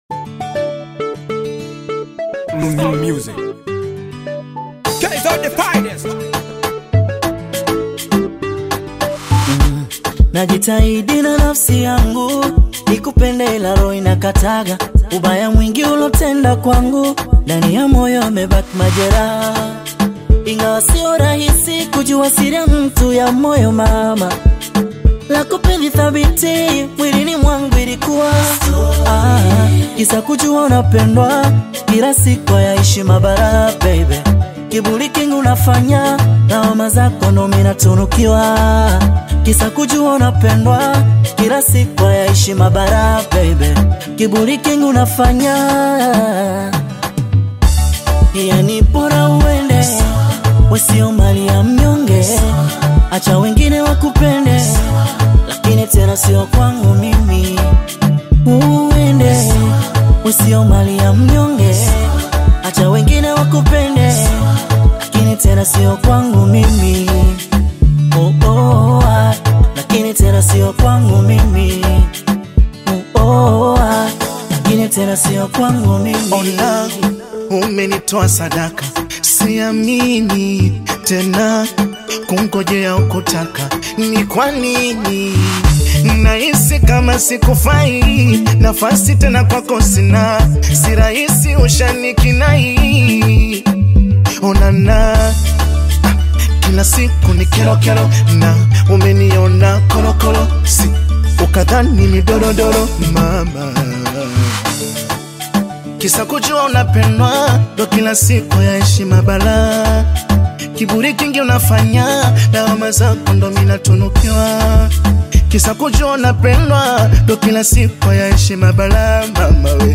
Tanzanian bongo fleva